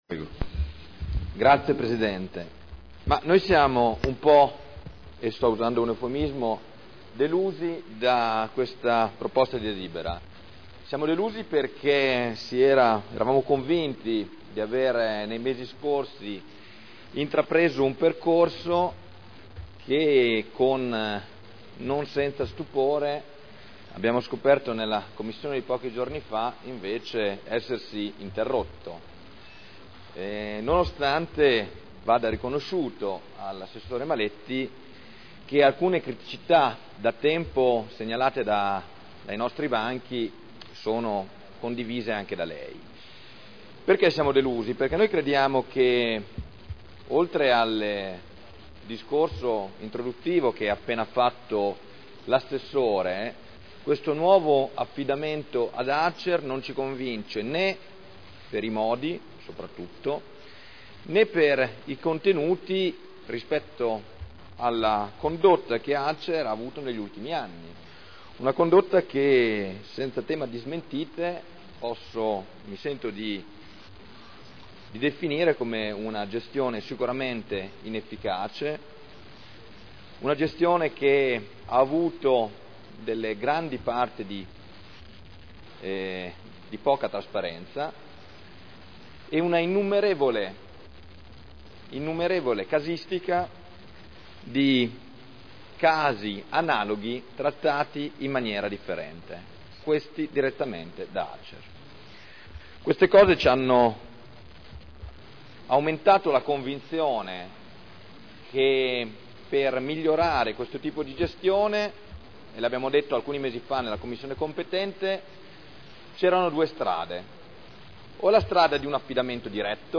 Seduta del 20/12/2010. Dibattito su delibera: Affidamento in concessione all’Azienda Casa Emilia Romagna del servizio di gestione del patrimonio di Edilizia Residenziale pubblica di proprietà del Comune – Approvazione Accordo Quadro provinciale e Contratto di Servizio del Comune di Modena (Commissione consiliare del 7 dicembre 2010)